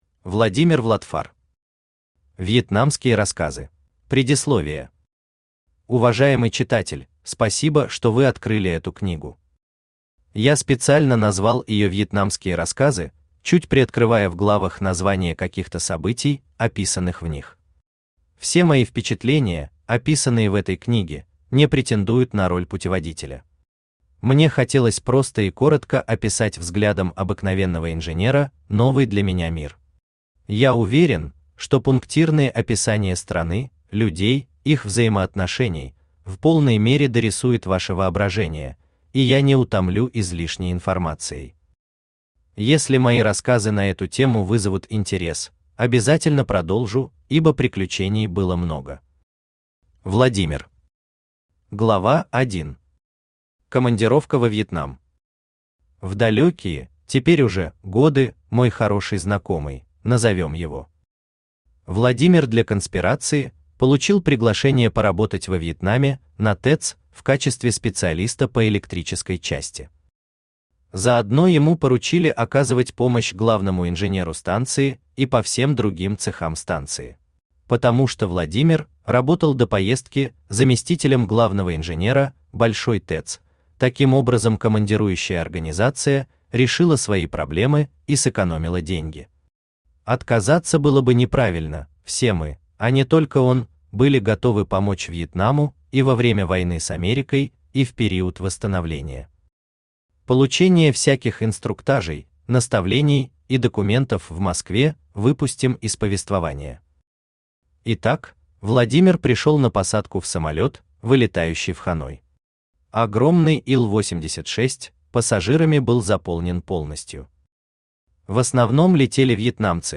Аудиокнига Вьетнамские рассказы | Библиотека аудиокниг
Aудиокнига Вьетнамские рассказы Автор Владимир Vladfar Читает аудиокнигу Авточтец ЛитРес.